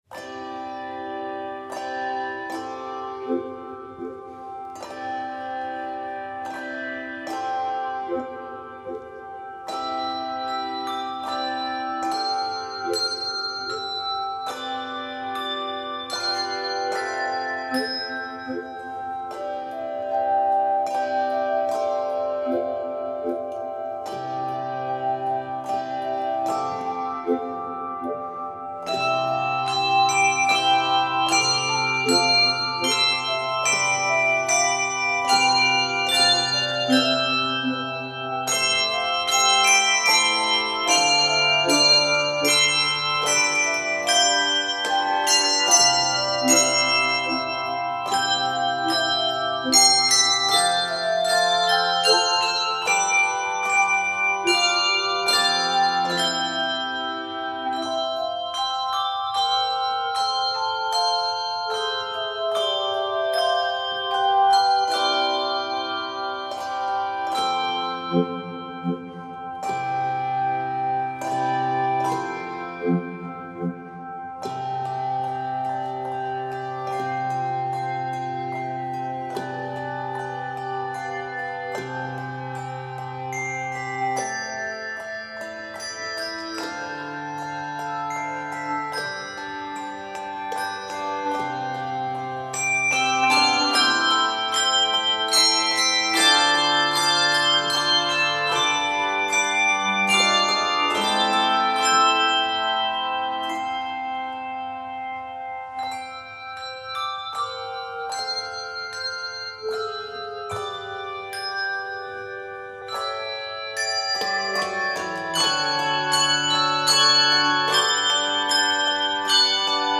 Polish carol